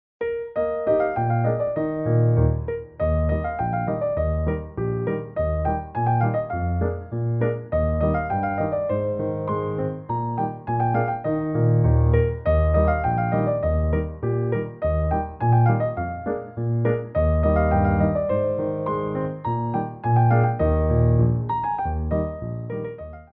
2/4 (16x8)